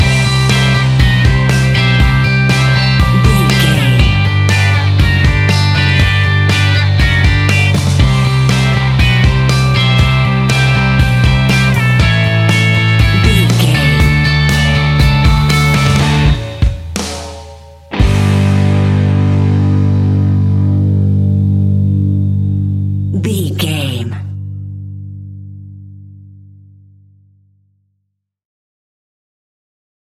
Ionian/Major
D
fun
energetic
uplifting
instrumentals
upbeat
rocking
groovy
guitars
bass
drums
piano
organ